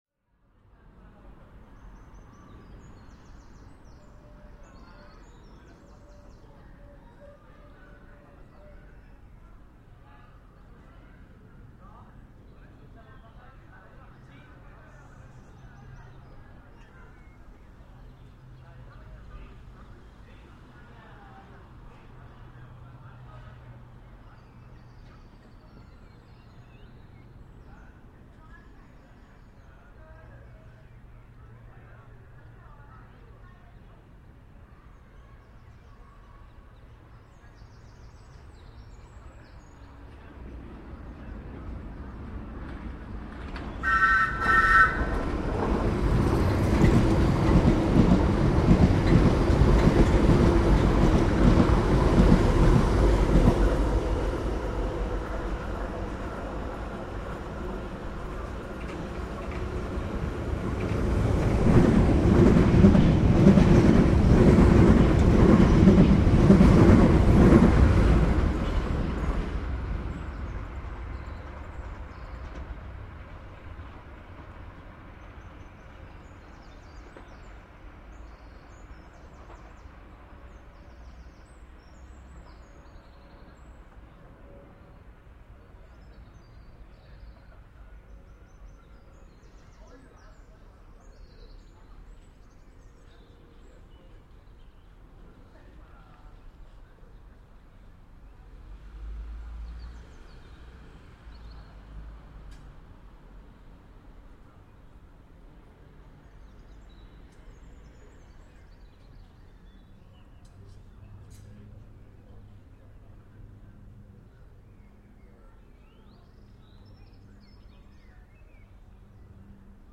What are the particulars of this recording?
Field recording from the London Underground by The London Sound Surveyim.